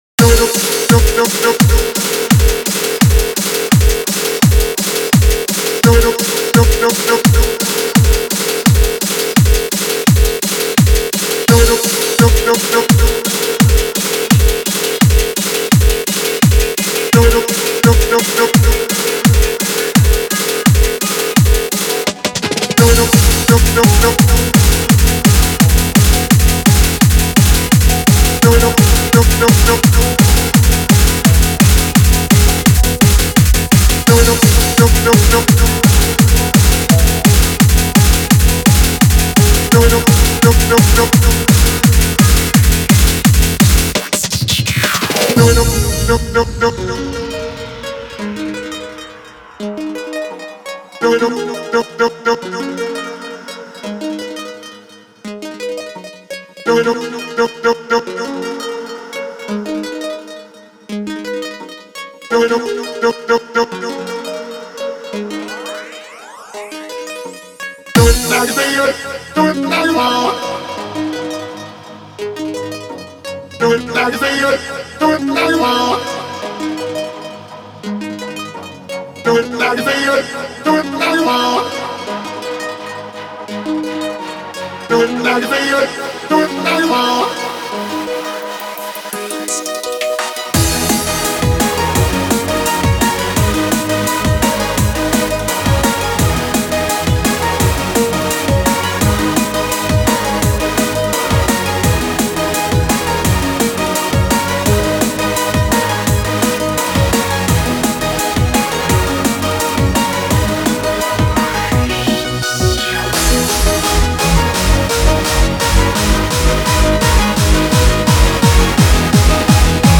data/localtracks/Japanese/J-Core